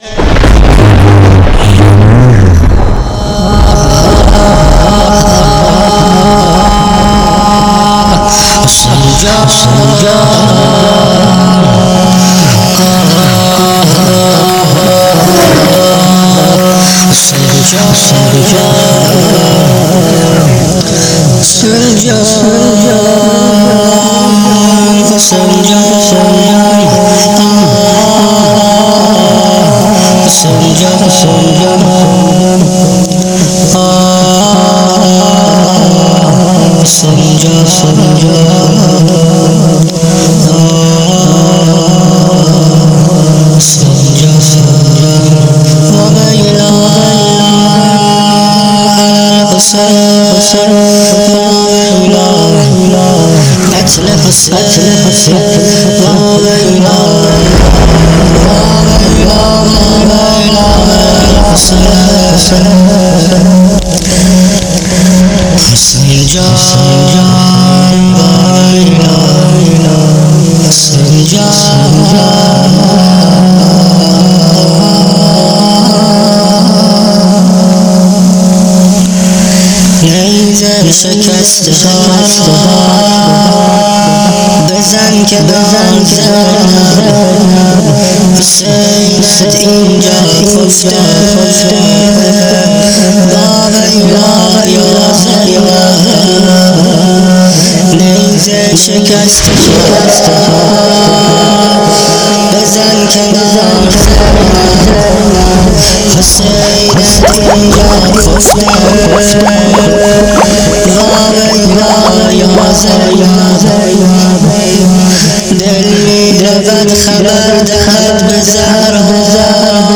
مداح کربلا مداح امام حسین